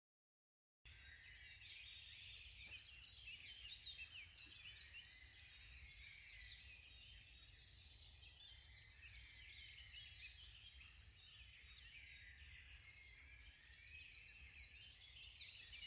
Dawn Chorus: one of nature’s most breathtaking phenomena - Dunsmore Living Landscape
It’s 4.30am on a cold April morning and I’m walking through Bubbenhall Wood listening to a symphony of clicks, churrs, chyaks and intense trills.
Then I instantly recognise a wren nearby, with its steady rattling stream of high pitched thrills and repeated notes, like a trigger happy gun firing away all these notes. A robin soon comes in, its bittersweet melodic warble, then a song thrush with its ‘tid it tid it tid it’ loud repetitive sound, unlike the wren and robin which have a repertoire of different notes.